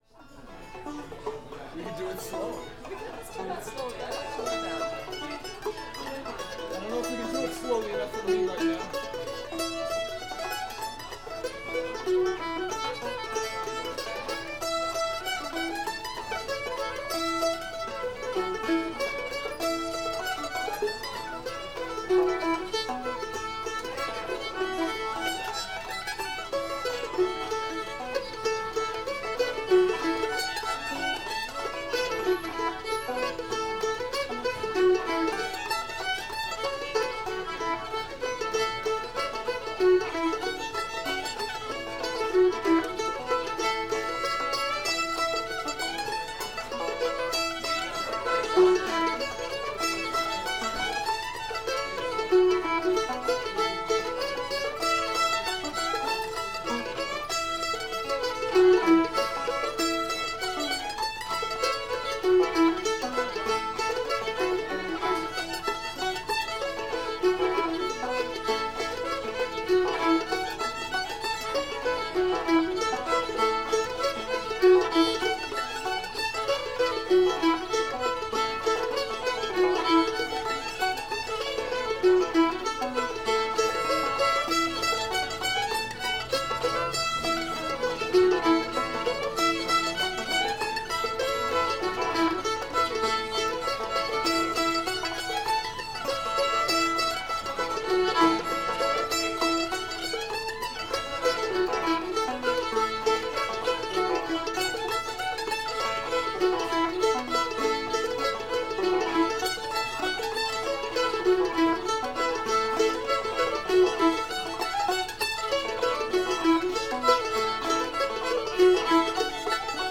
brushy run [A]